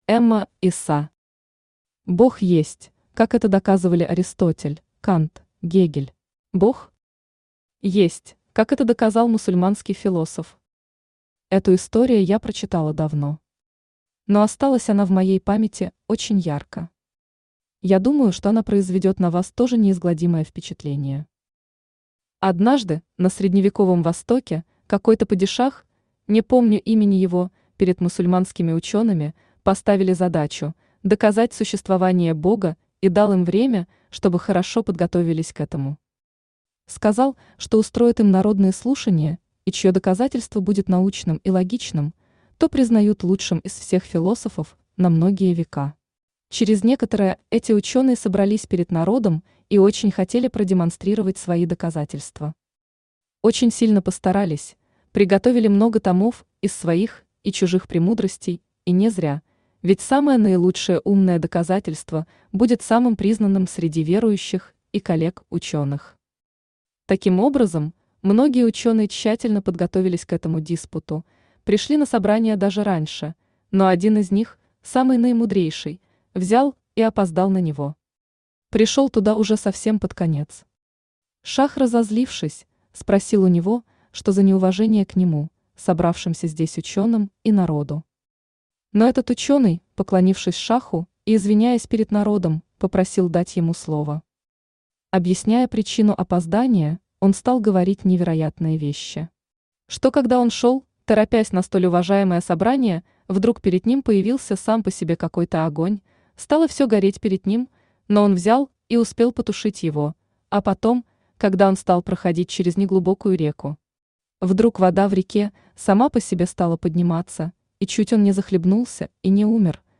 Аудиокнига Бог есть: Как это доказывали Аристотель, Кант, Гегель | Библиотека аудиокниг
Aудиокнига Бог есть: Как это доказывали Аристотель, Кант, Гегель Автор Эма Иса Читает аудиокнигу Авточтец ЛитРес.